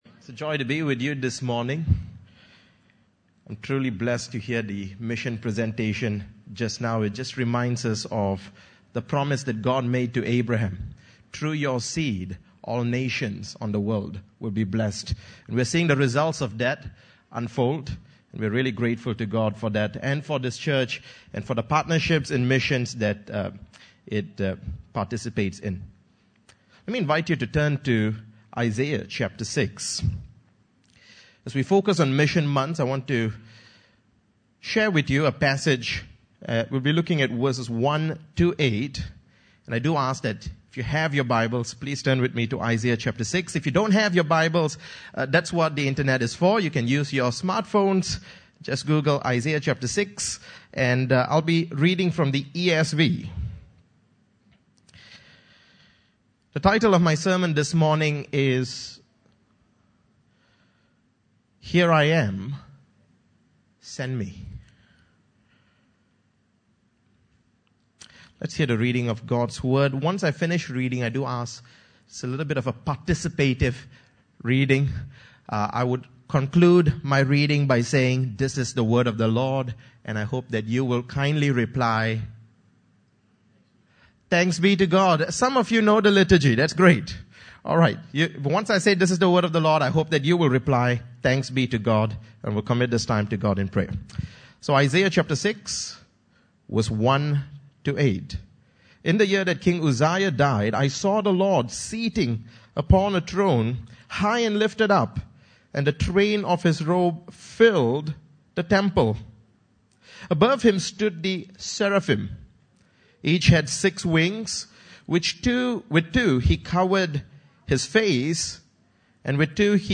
Passage: Isaiah 6:1-8 Service Type: Sunday Service (Desa ParkCity) « Expect Great Things From God